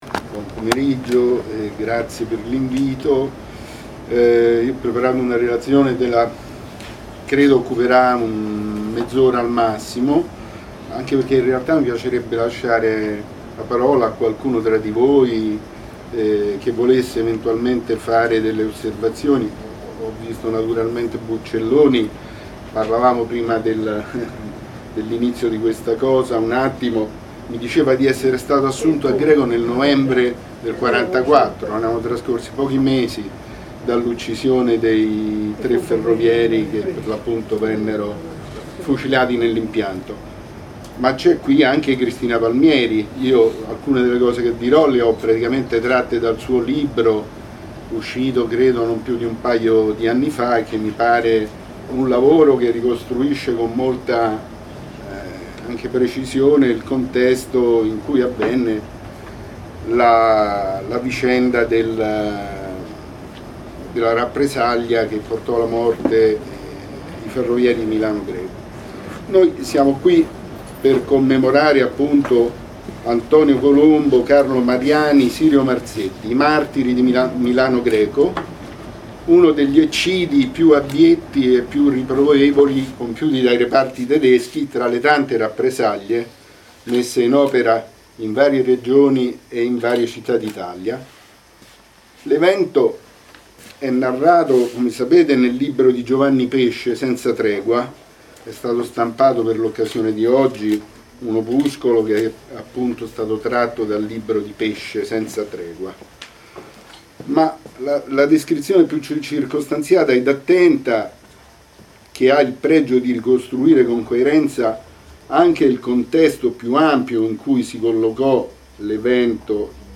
È disponibile la registrazione audio della conferenza del 16 aprile 2014: